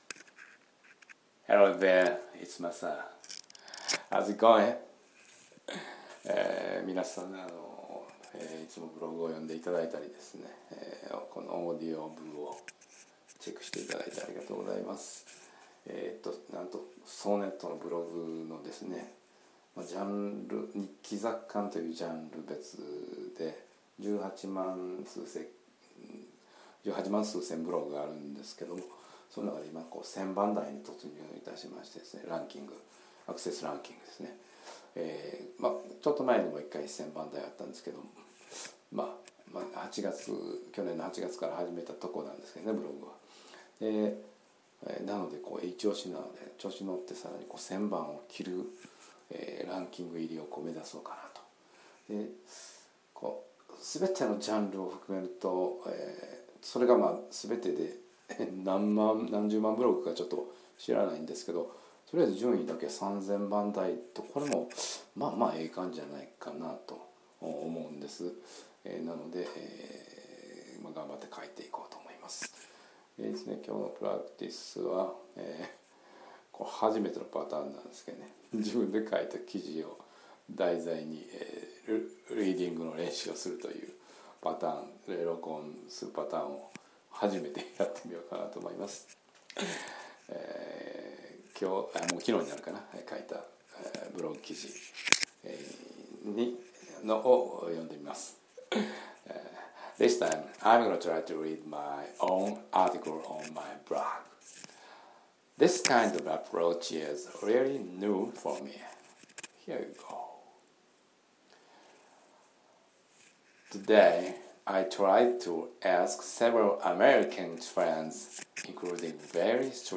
Reading My Own Article